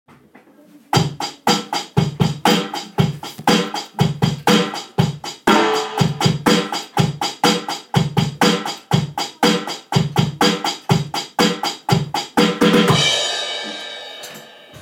basic rock beat